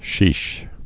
(shēsh)